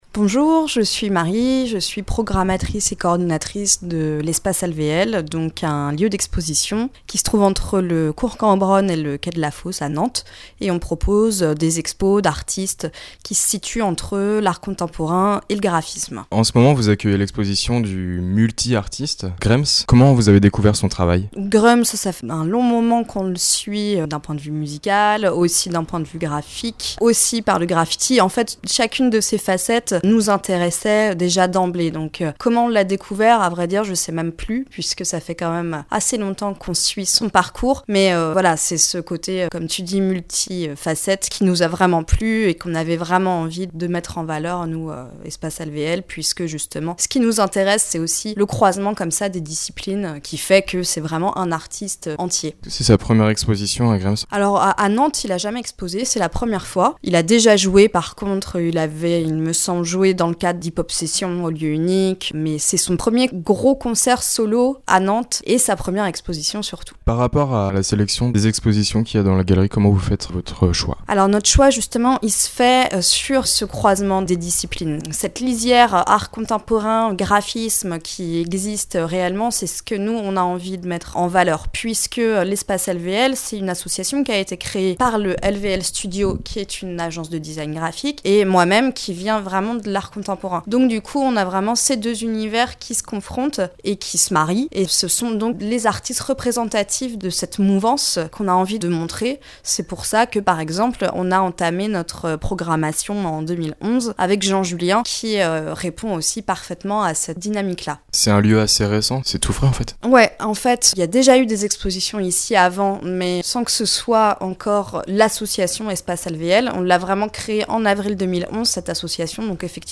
Reportage / documentaire